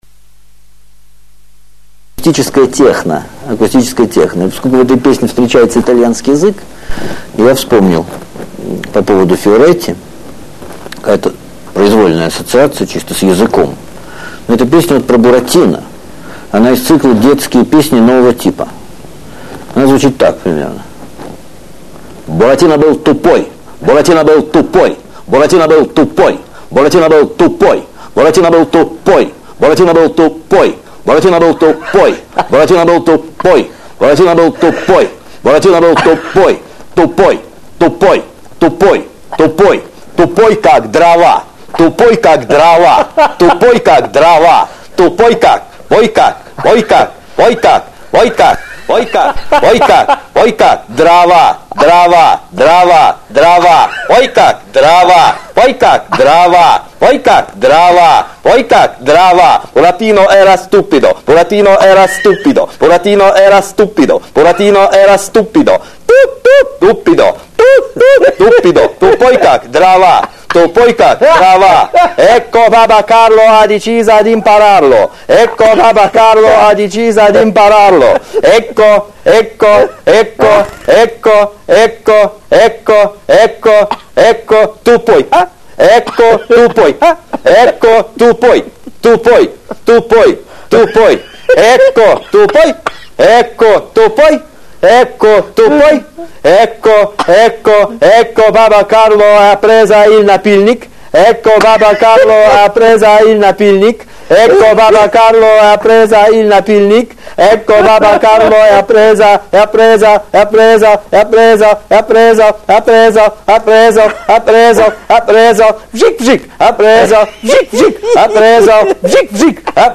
(акустическое техно)